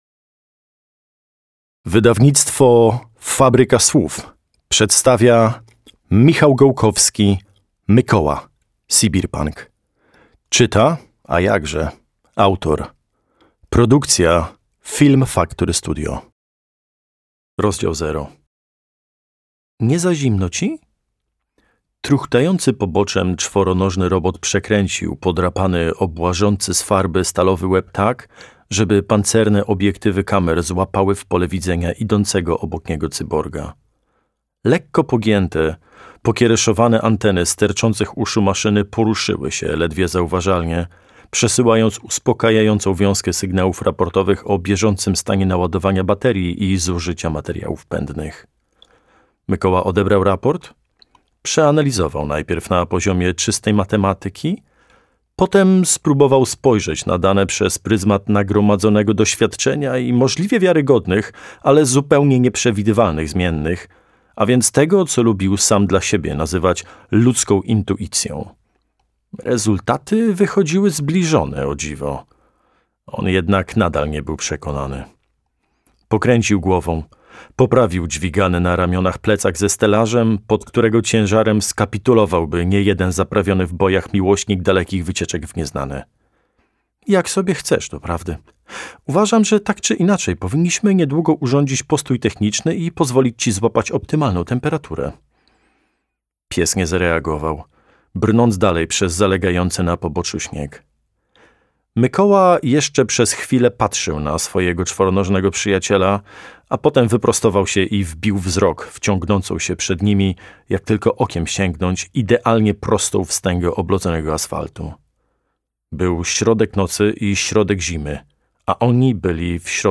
Mykoła - Michał Gołkowski - audiobook